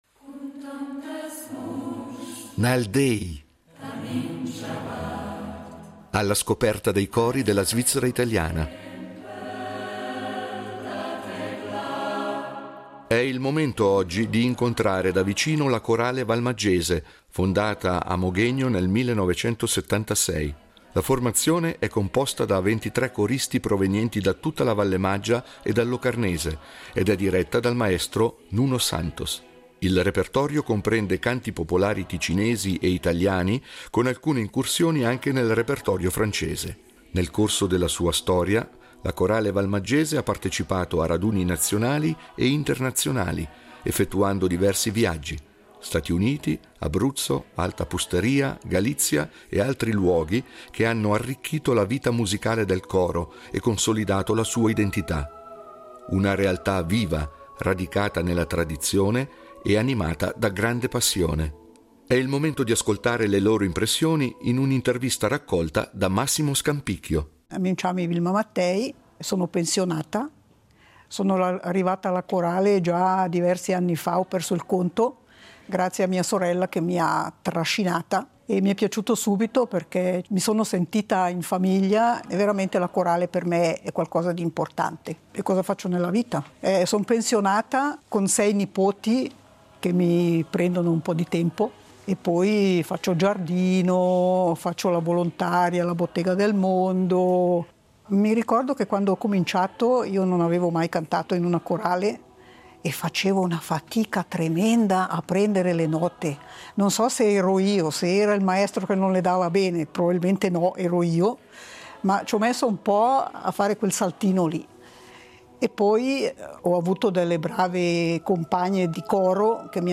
Nal déi, cori della svizzera italiana
composta da 23 coristi della Vallemaggia e del Locarnese
un repertorio di canti popolari ticinesi, italiani e francesi